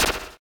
dispenseNoise.ogg